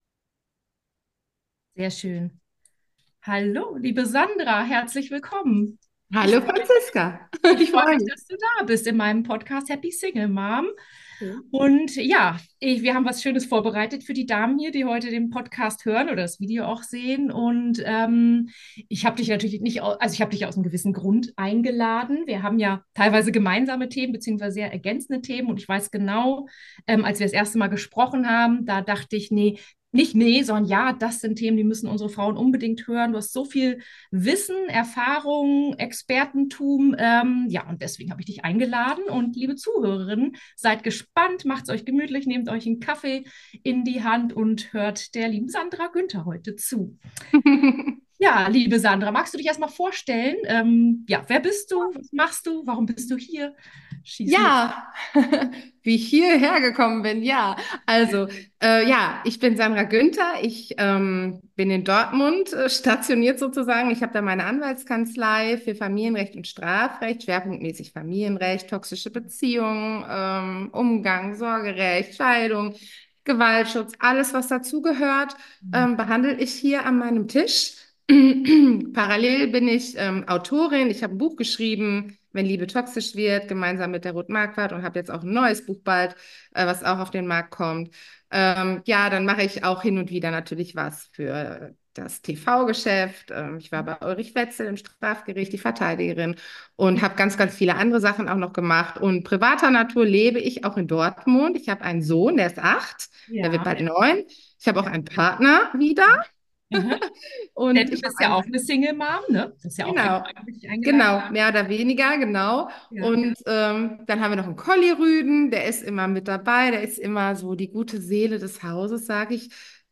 Special: Toxische Beziehungen – Interview